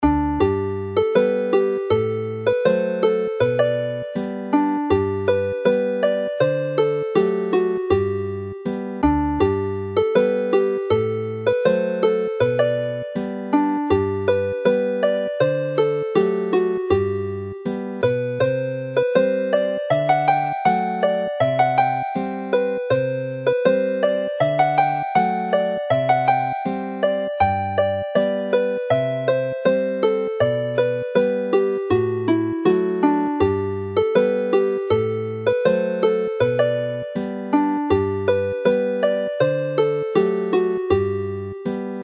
The lyrical Mwynen Merthyr is clearly from the South; Hufen Melyn is a familiar and catchy melody which does not fit into normal dance tune patterns, whilst Gwreiddyn y Pren Ffawydd is a standard 16-bar 2A 2B.
Play the tune slowly